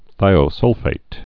(thīō-sŭlfāt)